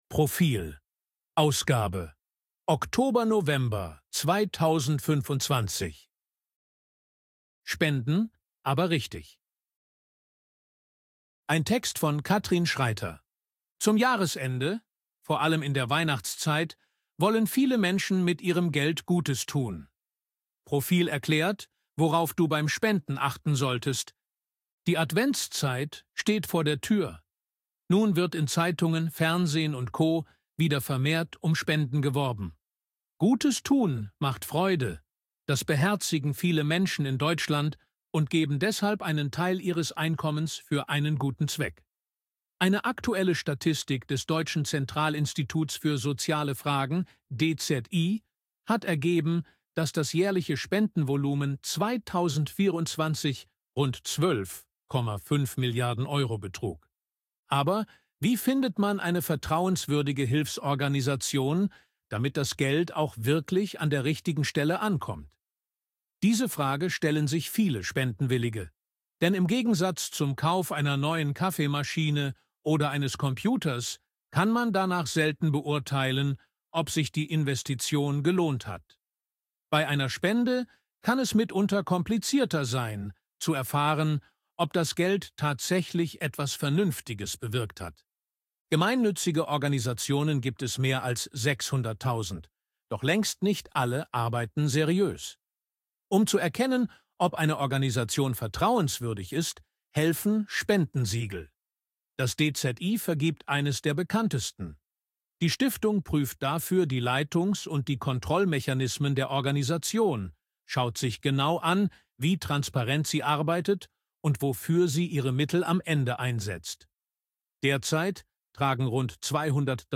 ElevenLabs_255_KI_Stimme_Mann_Service_Leben.ogg